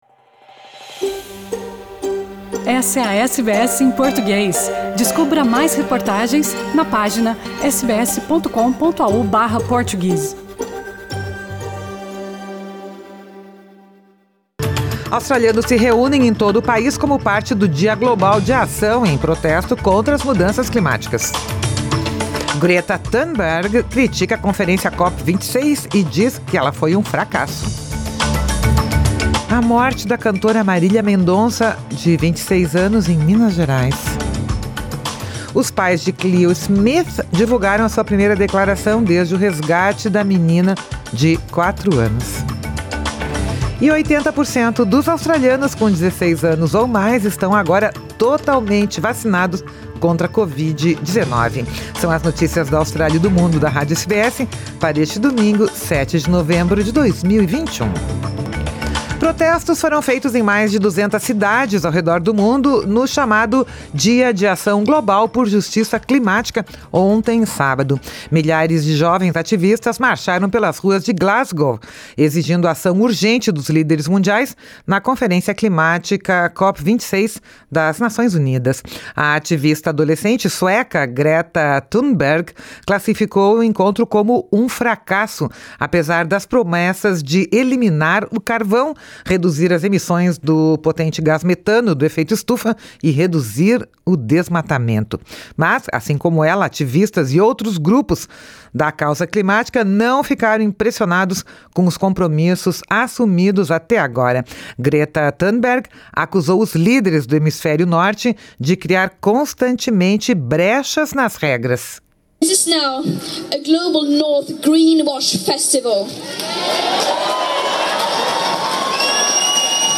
Oitenta por cento dos australianos com 16 anos ou mais estão agora totalmente vacinados contra a COVID-19. São as notícias da Austrália e do Mundo da Rádio SBS para este domingo, 7 de novembro de 2021.